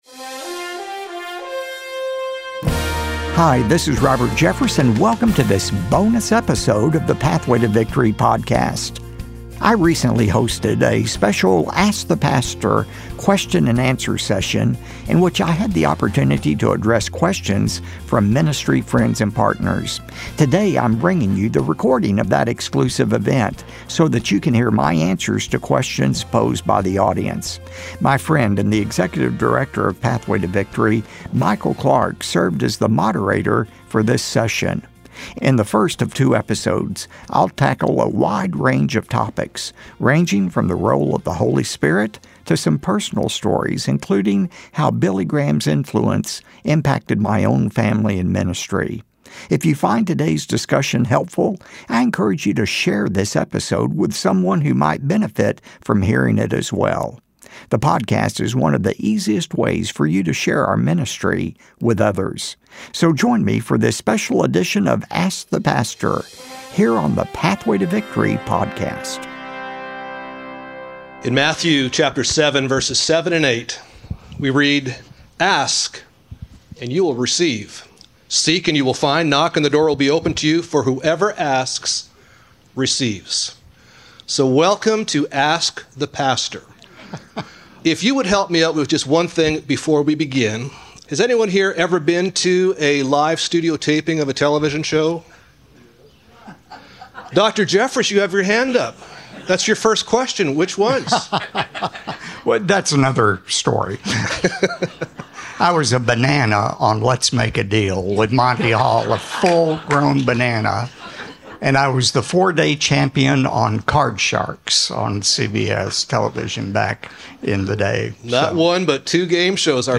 Part 1 of a special two-part Q&A session with Dr. Robert Jeffress recorded in front of a live audience in May 2025.